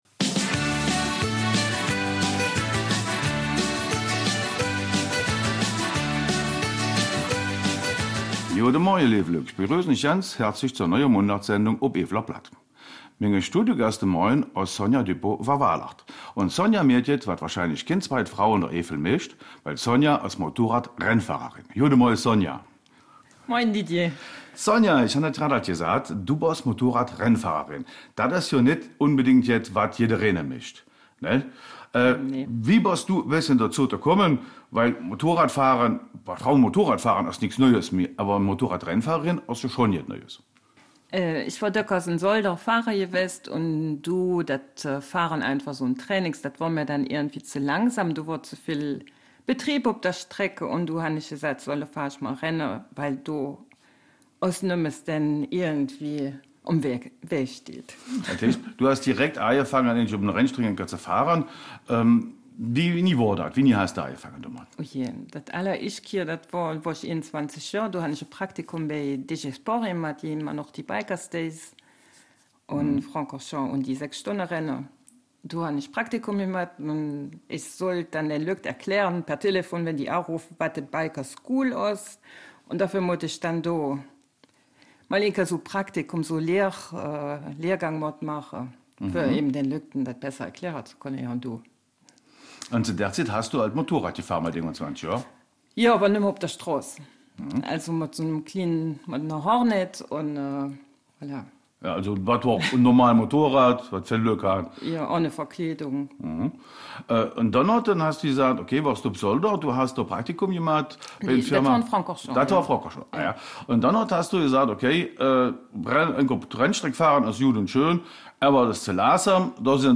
Eifeler Mundart: Karriere Motorradrennfahrerin